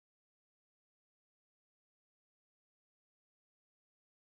blank.wav